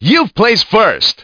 Speech
1 channel